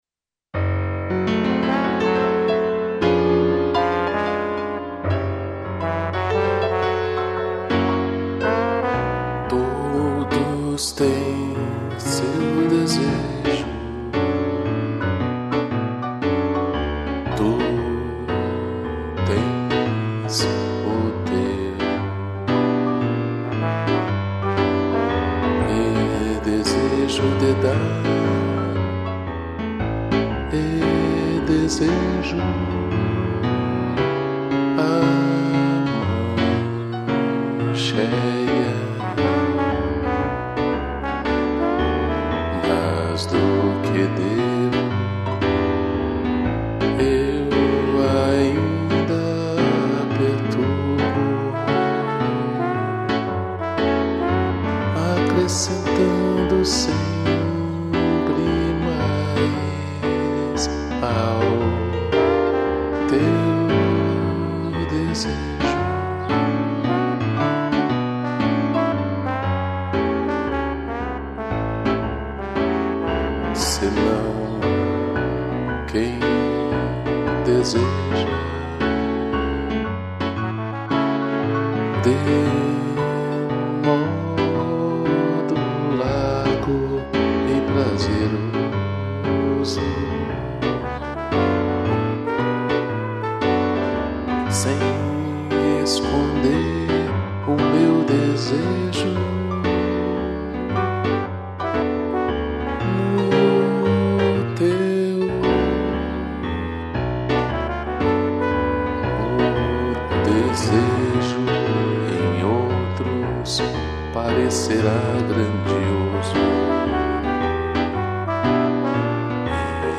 2 pianos e trombone